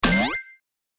音效
hit.mp3